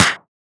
• Hand Clap Sample F# Key 21.wav
Royality free clap sound clip - kick tuned to the F# note. Loudest frequency: 3338Hz
hand-clap-sample-f-sharp-key-21-qUq.wav